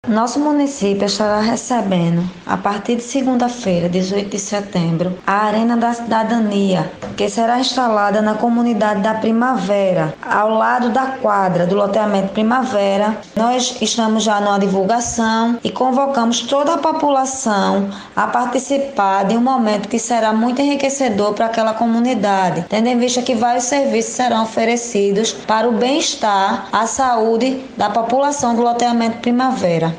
A secretária de Desenvolvimento e Assistência Social, Valquíria Marinho, deixou um convite à população.